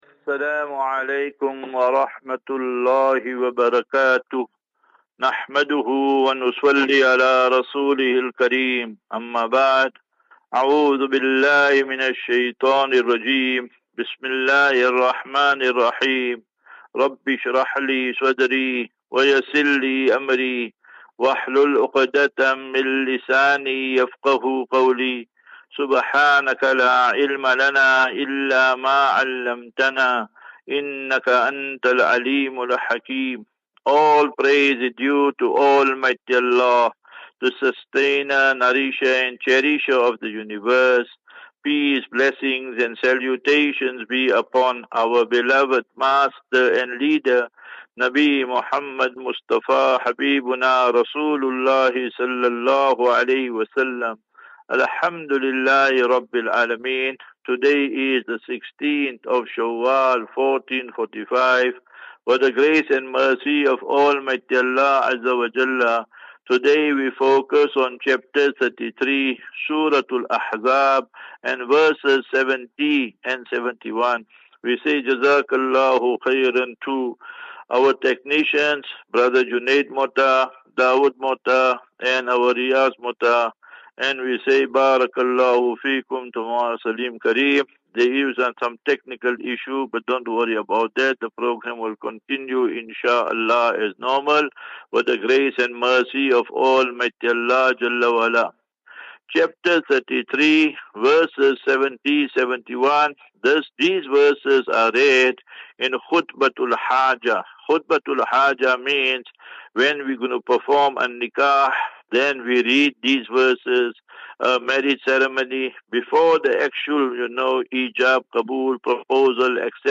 General Naseeha.